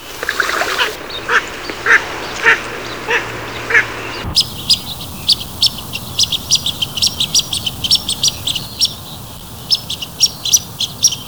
Blue-winged Teal
VOZ: Un agudo silbido doble; o un graznido.